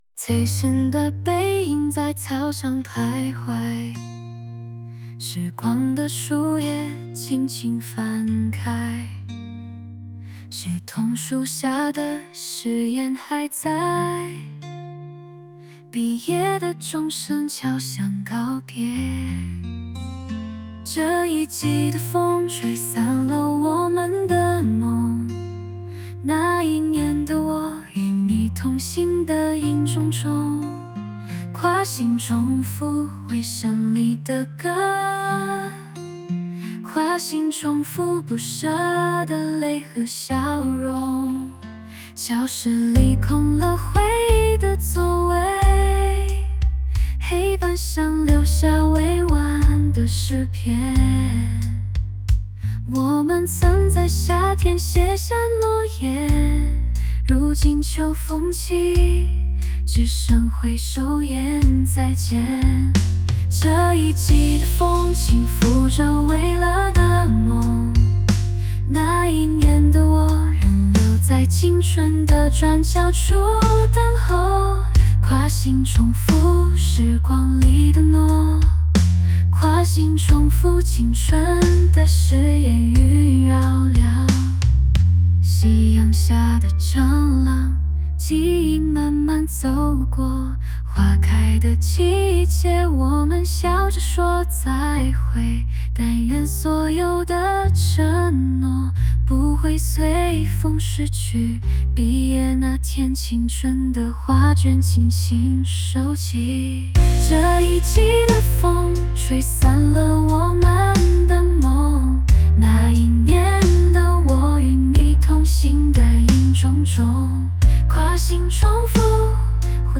咱也不懂都有啥音乐风格，bing一下，然后找到对应的英文音乐风格填进去即可，我这里使用的是流行音乐风格、
结尾会直接中断~~